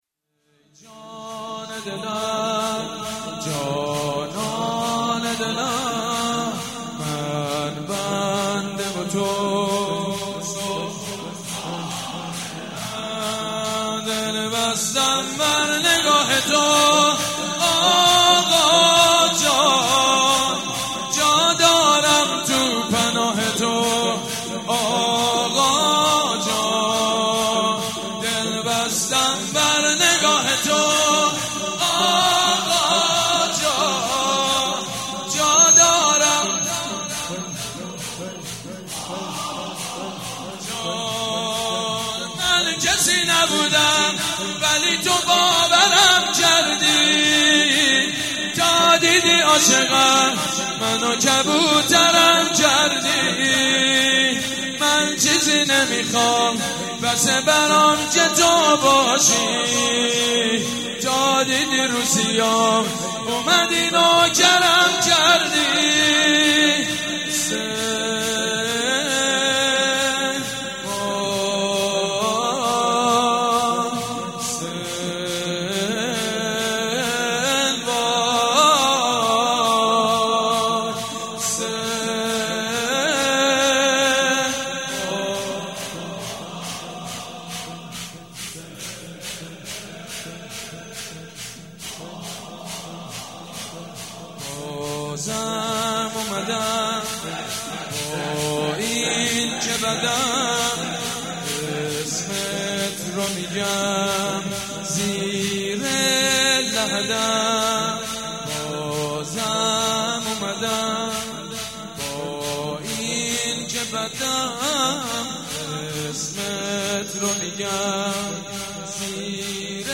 شب اول محرم الحرام 1394 | هیات ریحانه الحسین | حاج سید مجید بنی فاطمه
ای جان دلم جانان دلم | شور | حضرت امام حسین علیه السلام